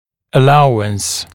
[ə’lauəns][э’лауэнс]допуск, допустимое отклонение; допущение, дозволение, разрешение